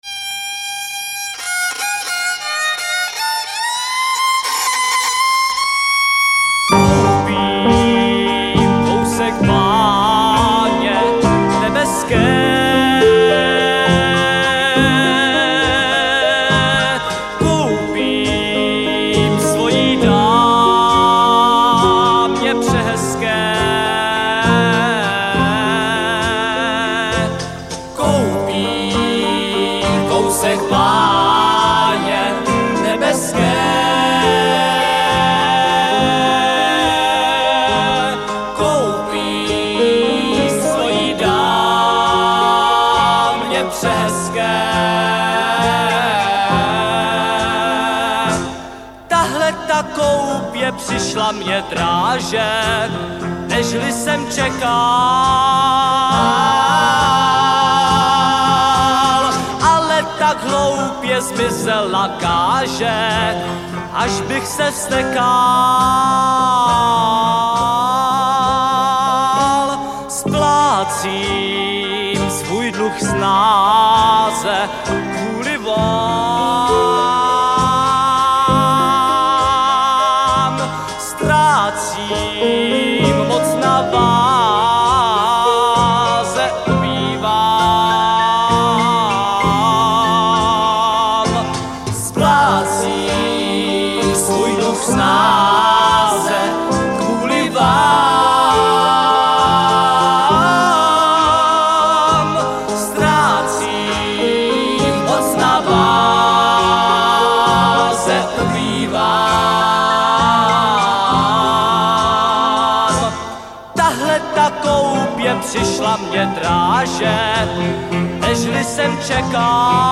prázdninový pojízdný písničkový kabaret.
Nahráno v rozhlasovém studiu ČR Plzeň 1968.